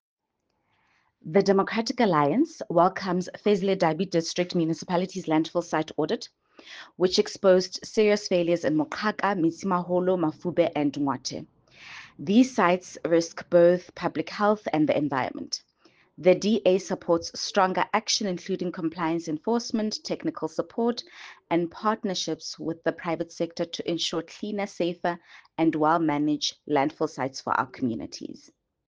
Sesotho soundbites by Cllr Mbali Mnaba.